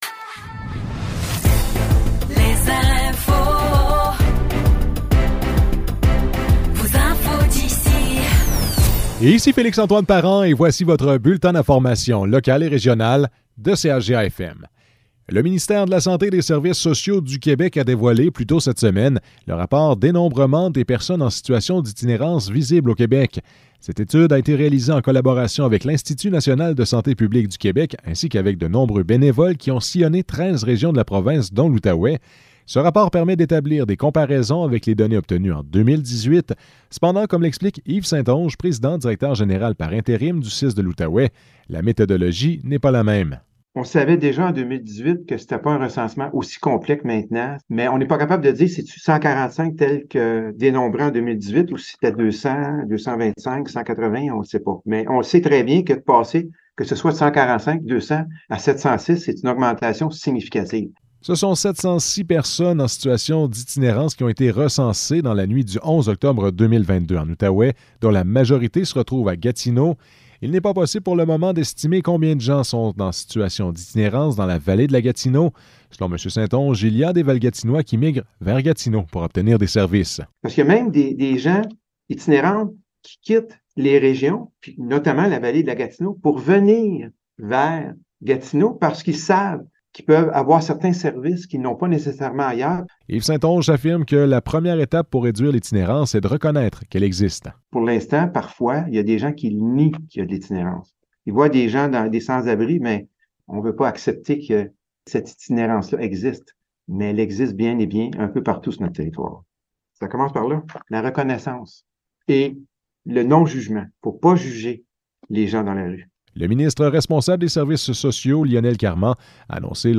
Nouvelles locales - 15 septembre 2023 - 12 h